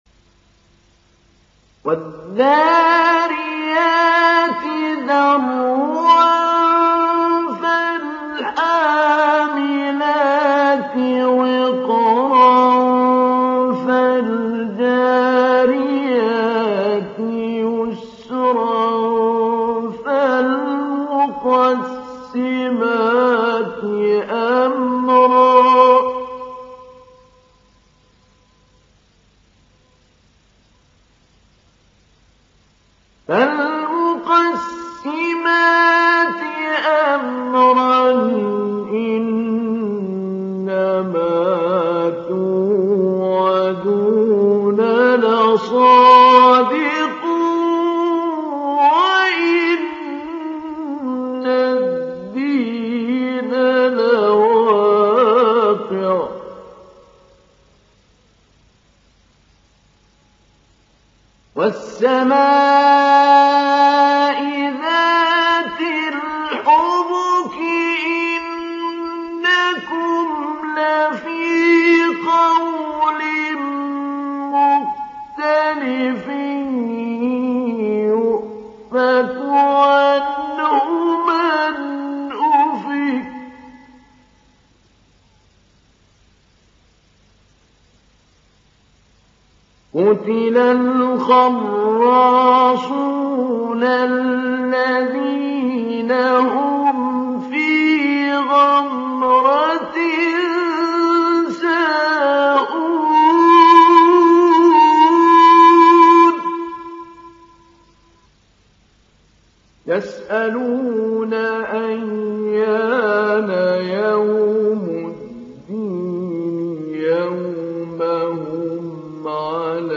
সূরা আয-যারিয়াত ডাউনলোড mp3 Mahmoud Ali Albanna Mujawwad উপন্যাস Hafs থেকে Asim, ডাউনলোড করুন এবং কুরআন শুনুন mp3 সম্পূর্ণ সরাসরি লিঙ্ক